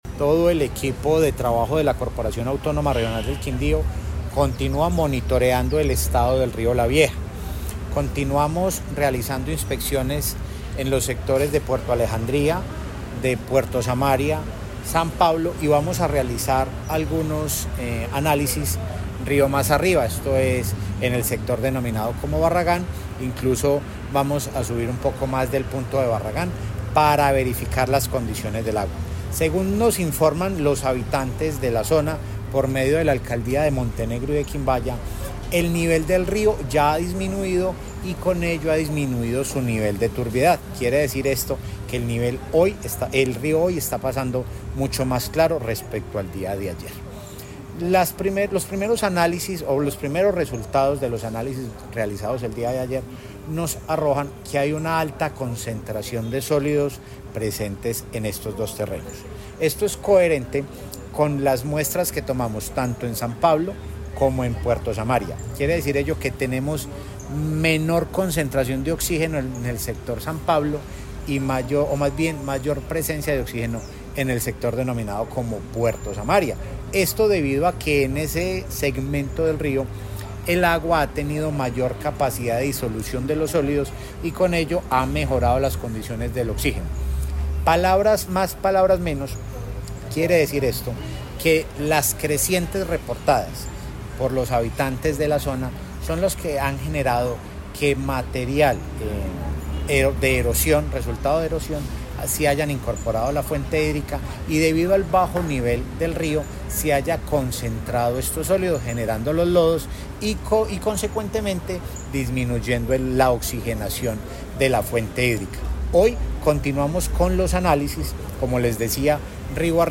Director encargado CRQ